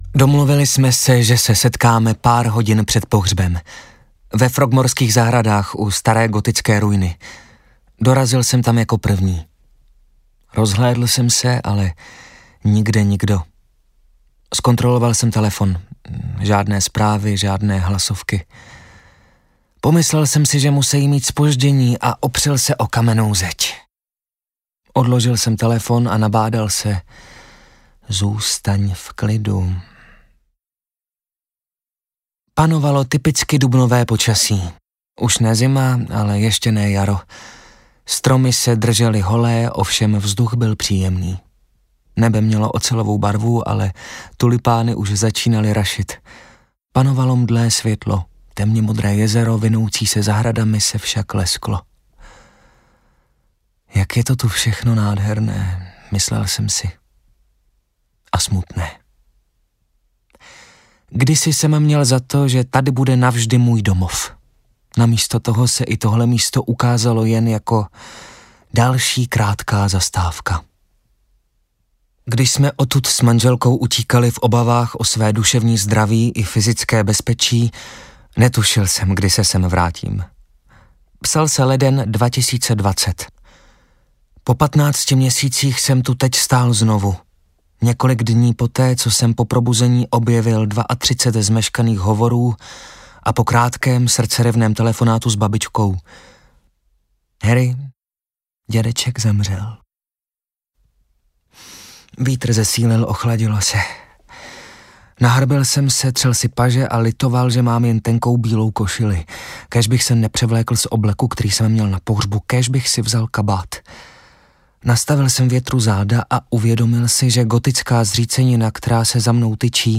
Audiokniha Náhradník - Princ Harry | ProgresGuru
audiokniha